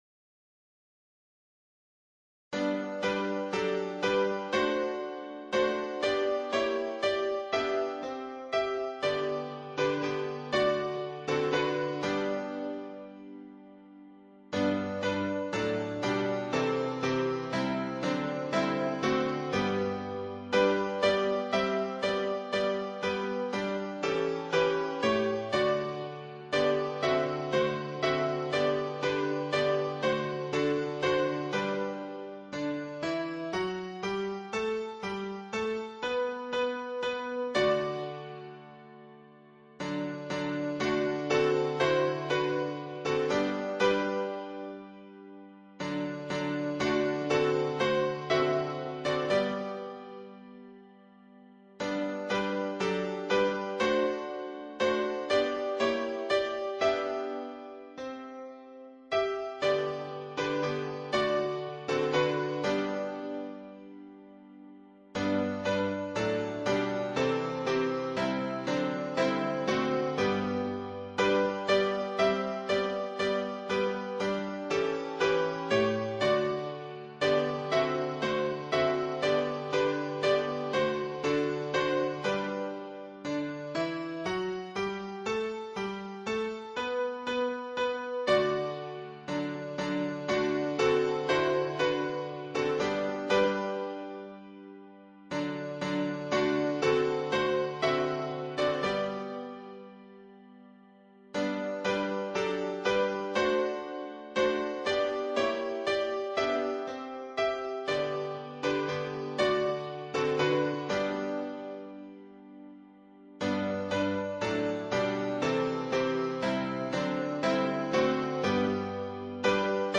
伴奏
原唱